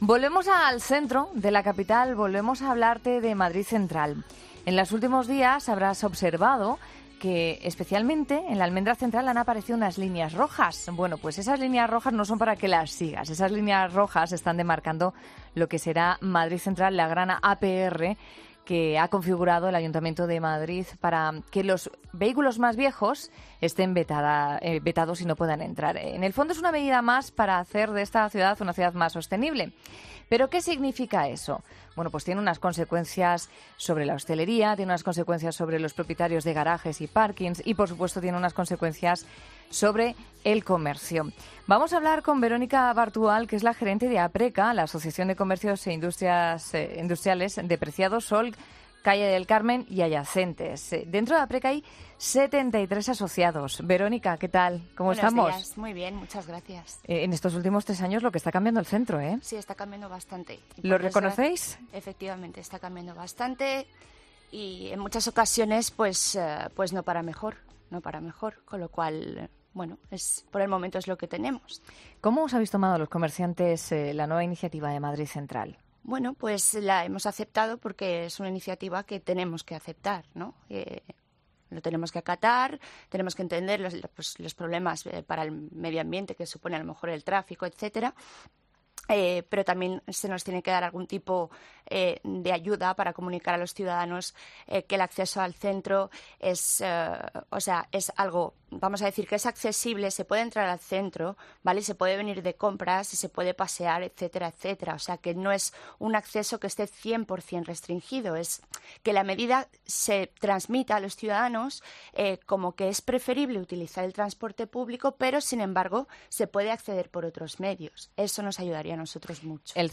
Uno de los gremios más afectados es el de los comerciantes, que pueden perder clientes. Hablamos con APRECA.